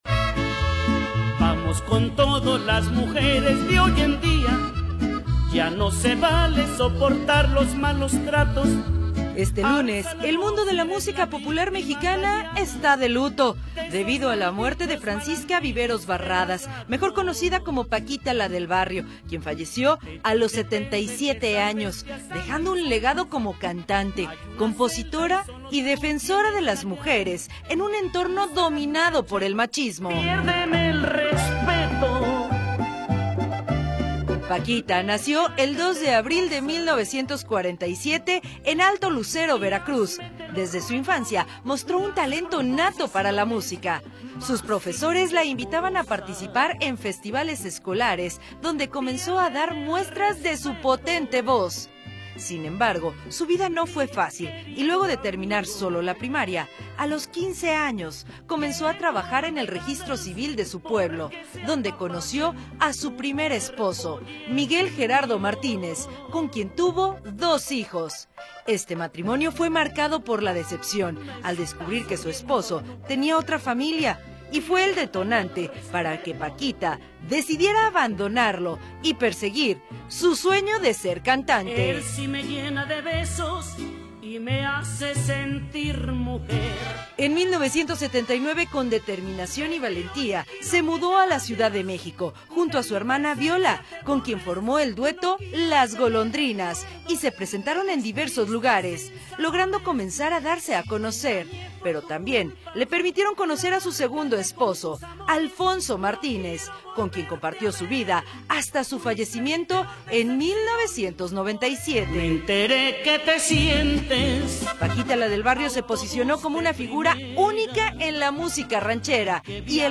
Escucha la semblanza completa.